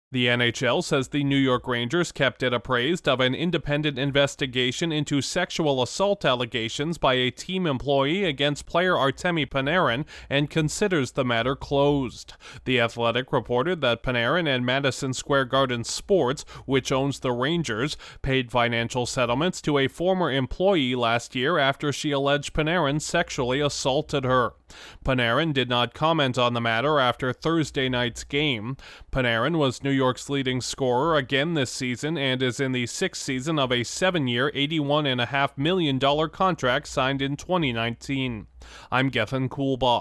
An NHL star in New York City has been investigated for sexual assault. Correspondent